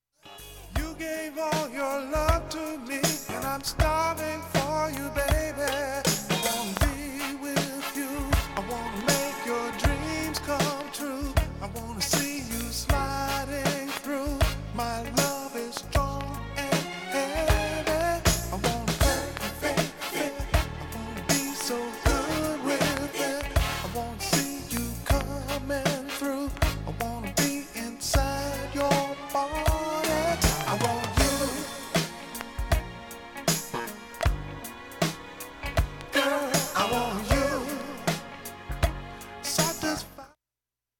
盤面きれいです、音質良好全曲試聴済み。
40秒の間に周回プツ出ますがかすかです
メロウ・チューンA2